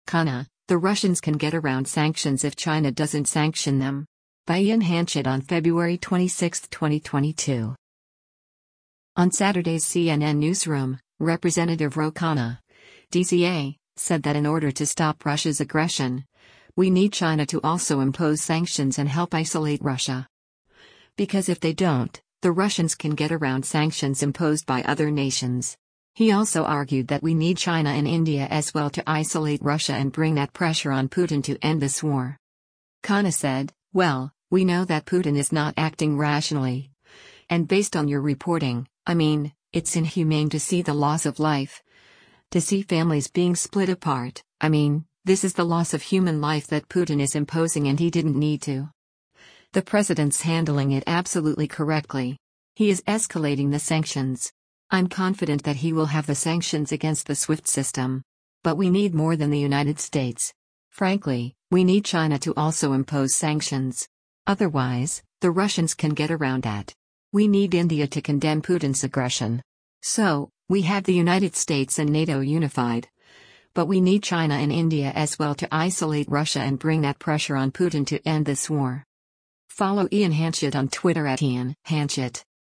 On Saturday’s “CNN Newsroom,” Rep. Ro Khanna (D-CA) said that in order to stop Russia’s aggression, “we need China to also impose sanctions” and help isolate Russia.